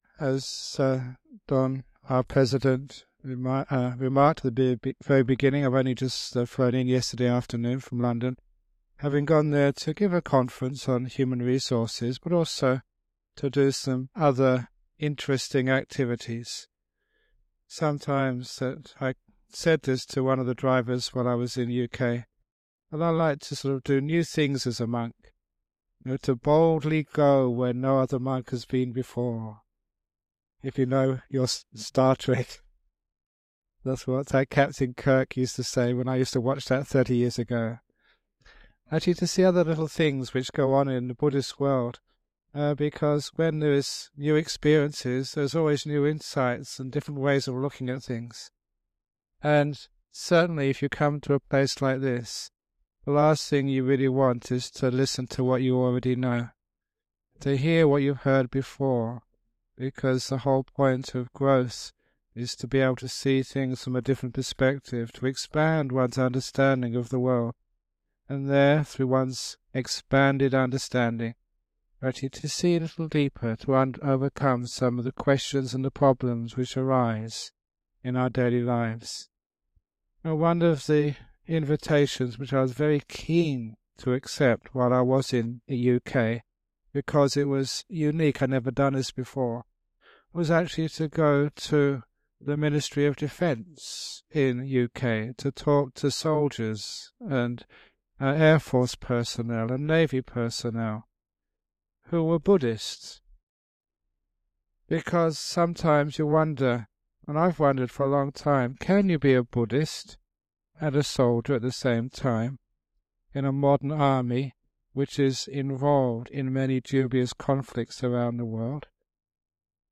This talk is a thoughtful exploration of the ethics of serving in the military and various situations in life where simplistic, rules-based ethics falls short.